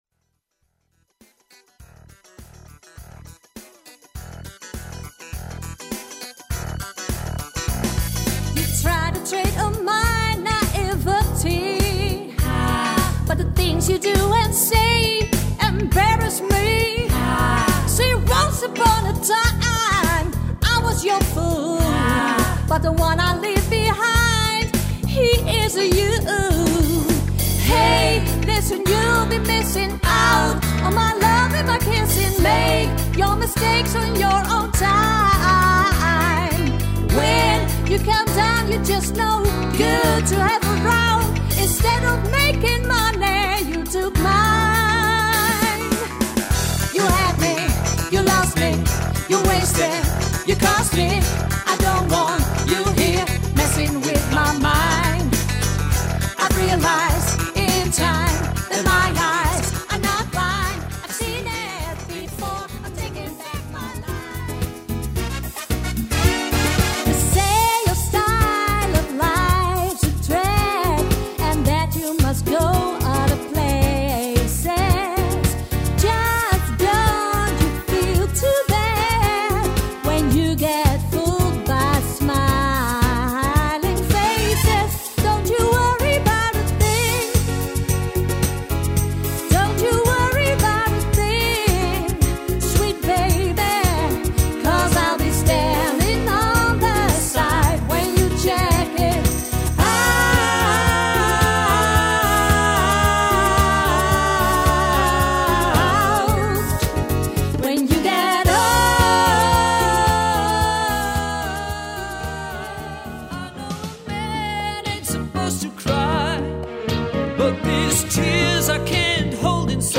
Een soulformatie